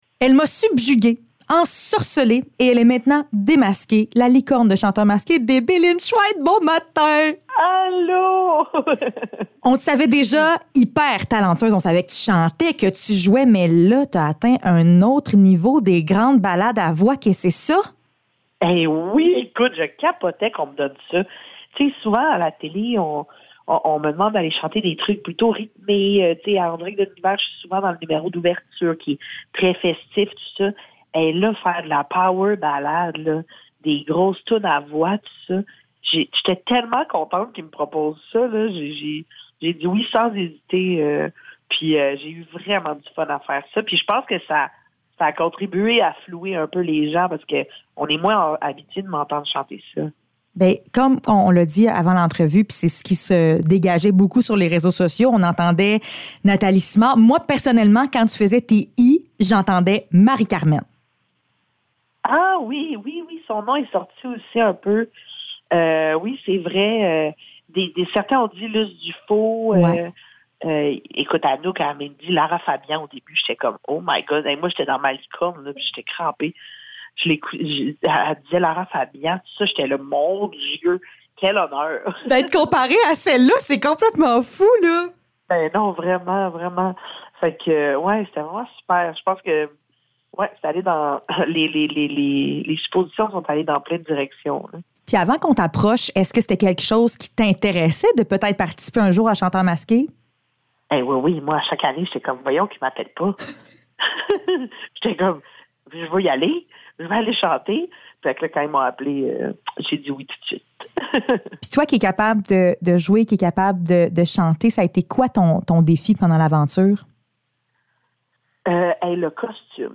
Chanteur masqué : Entrevue avec Debbie Lynch-White